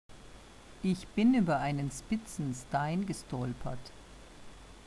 Mit s-p und s-t gesprochene ‘spitze Steine’ gibt es – wenn überhaupt – nur im Norden Deutschlands, genauer gesagt im Hamburger Raum.
Hamburg
Norddeutschland.wma